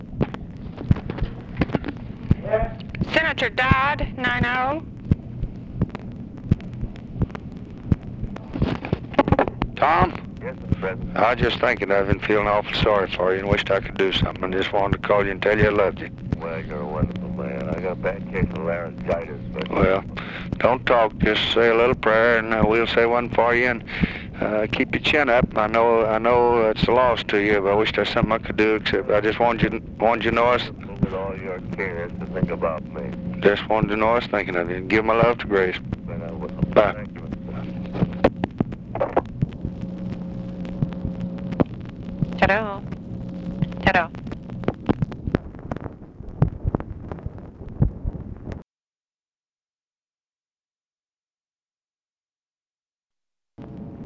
Conversation with THOMAS DODD, May 6, 1965
Secret White House Tapes